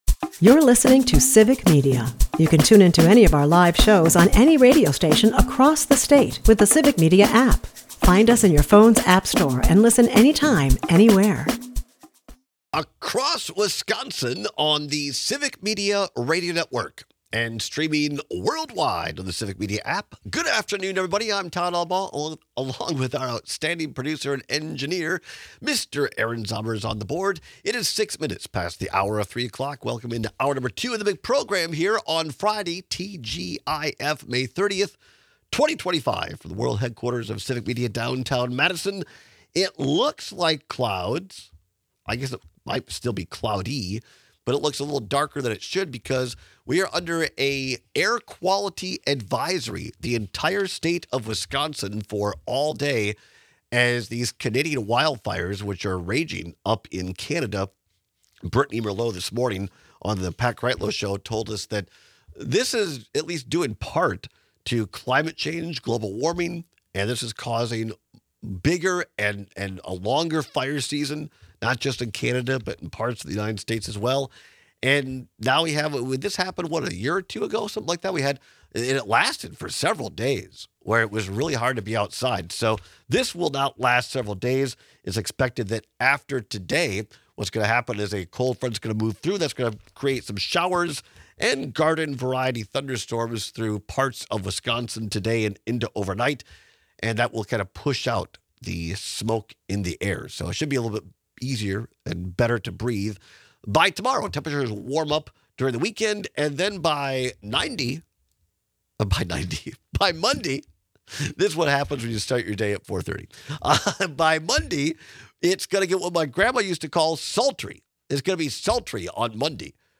We take your calls and texts.